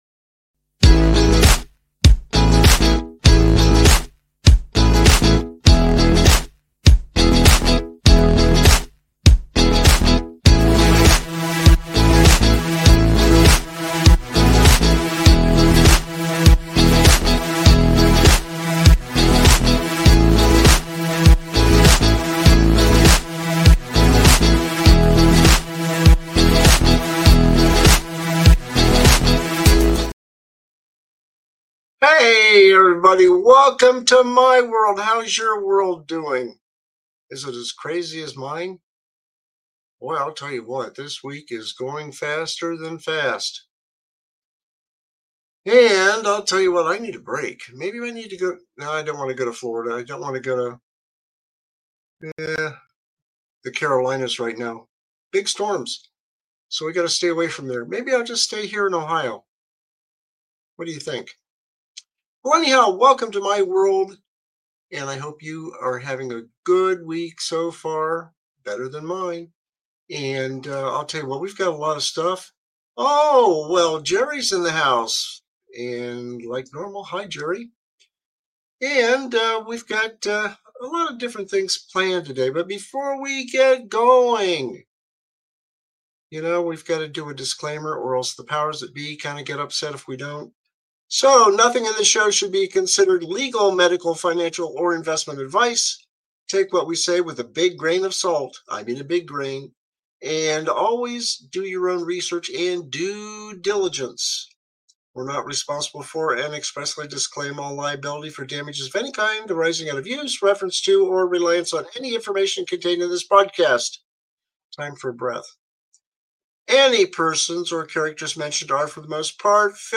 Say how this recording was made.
Get ready to laugh along with some classic TV outtakes featuring legends like Tim Conway and Harvey Korman from The Carol Burnett Show.